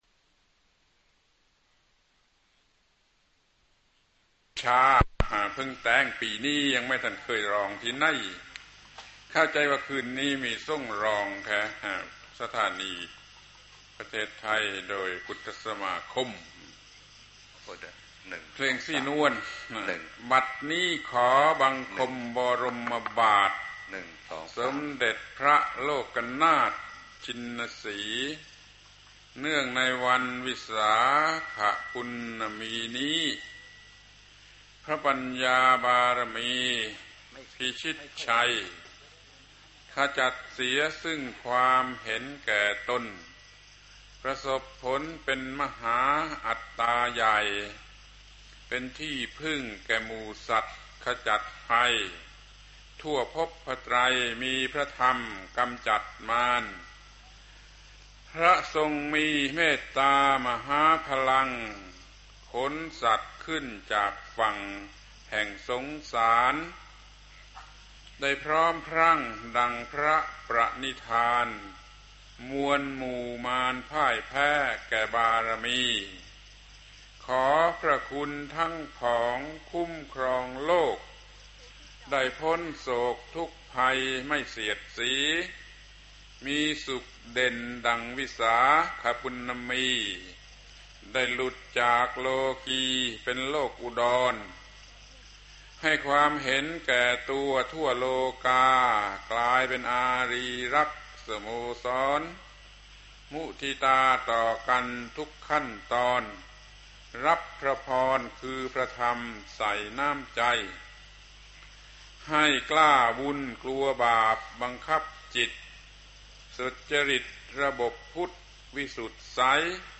พระธรรมโกศาจารย์ (พุทธทาสภิกขุ) - วิสาขบูชาเทศนา ปี 2521 กัณฑ์ที่ 2 (ค่ำ)